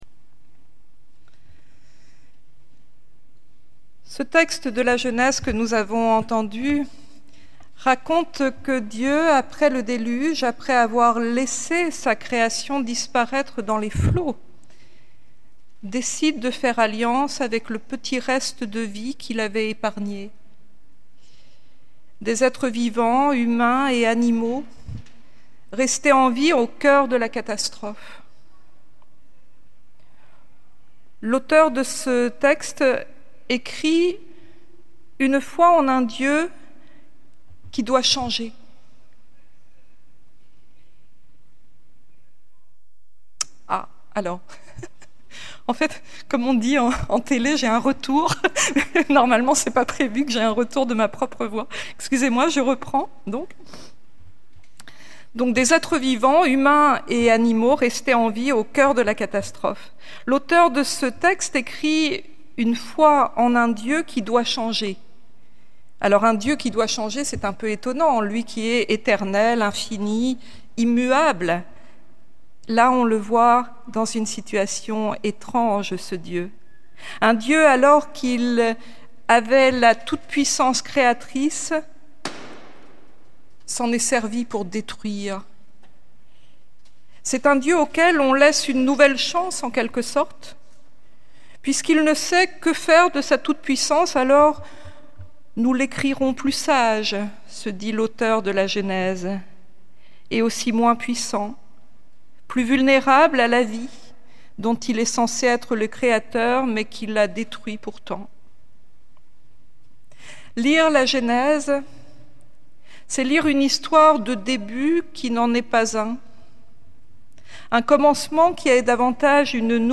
Culte du 15 septembre 2024